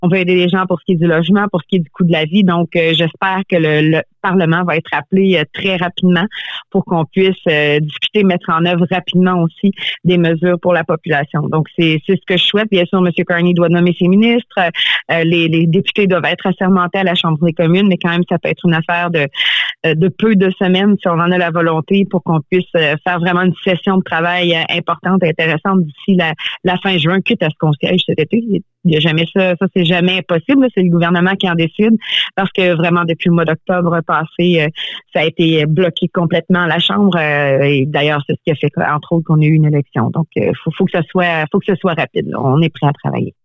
Elle est reconnaissante de cette confiance que lui accorde ses électeurs, a-t-elle affirmé en entrevue à CFMF ce mardi après-midi :